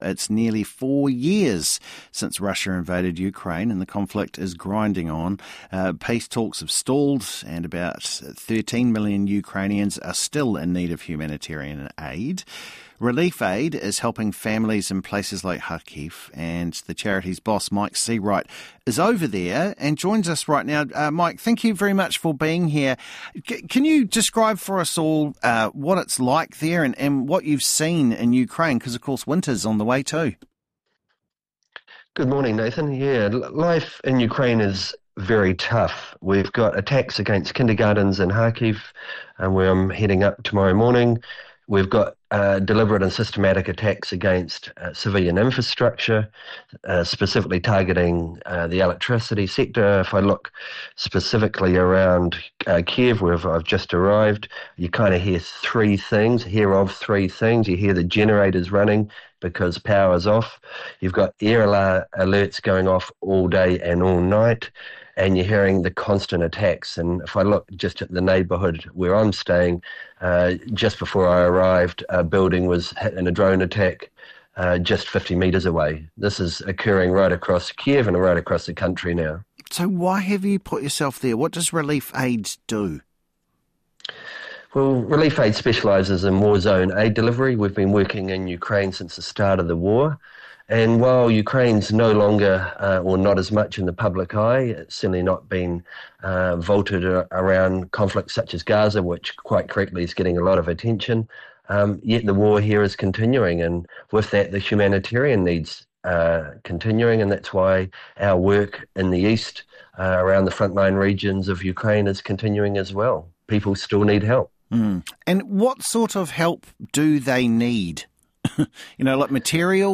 Live From Kyiv